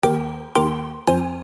• Качество: 320, Stereo
без слов
электронные
стук
Очень короткий звук на сообщения